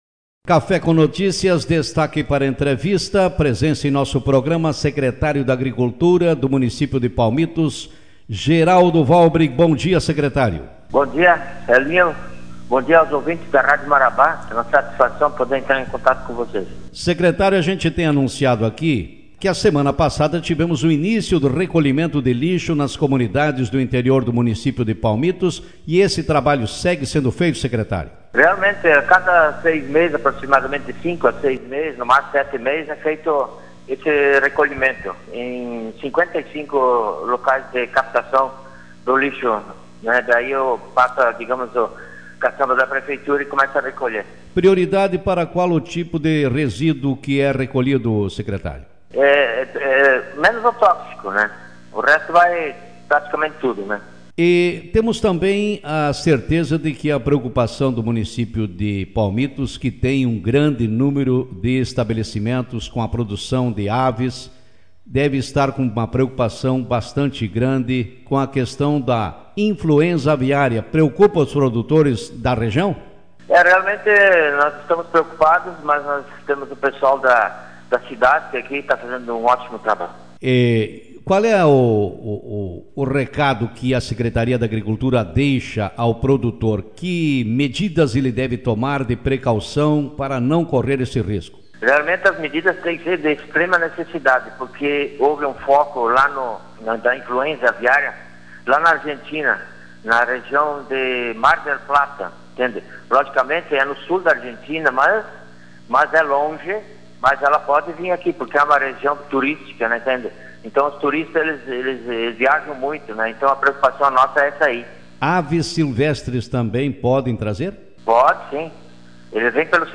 Secretário da agricultura de Palmitos fala sobre cuidados com a influenza aviária Autor: Rádio Marabá 13/03/2023 Manchete Nesta manhã o secretário da agricultura, de Palmitos, Geraldo Geraldo Henrique Walhbrink, participou do programa Café com Notícias e falou sobre os trabalhos desenvolvidos na secretaria, entre os quais, orientações aos produtores com relação a influenza aviária.